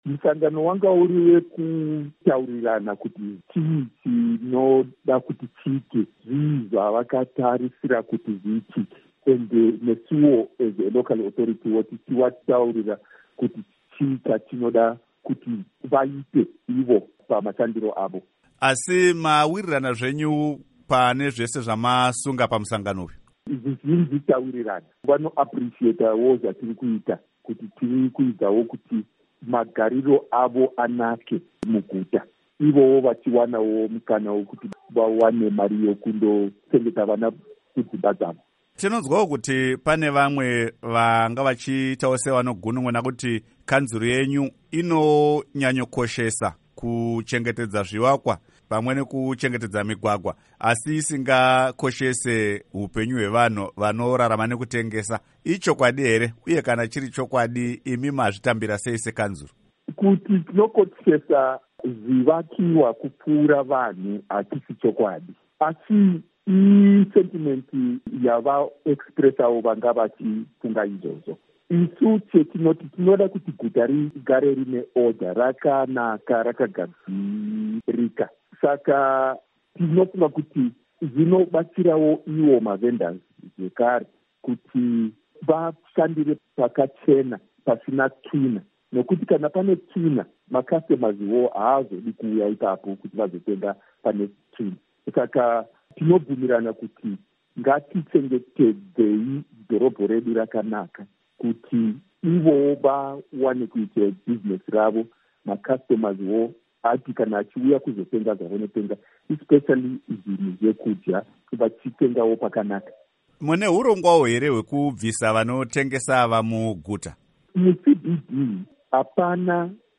Hurukuro naVaMartin Moyo